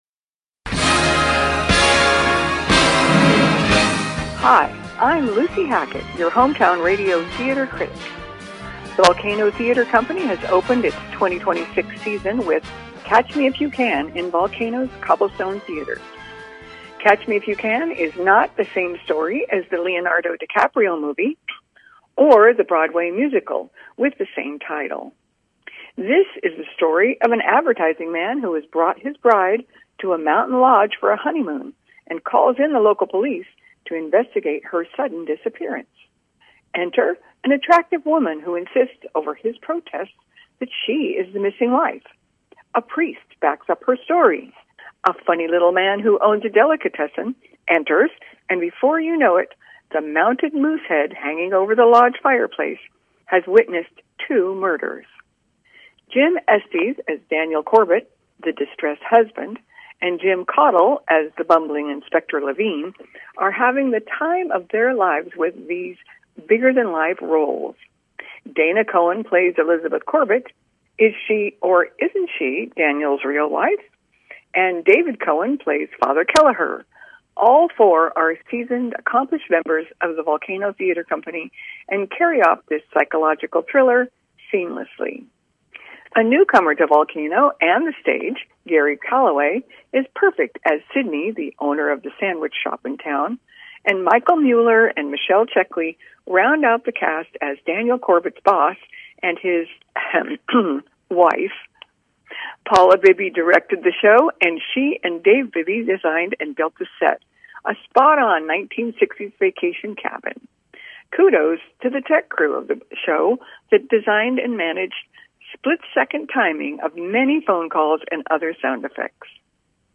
KVGC review of Keep On Laughing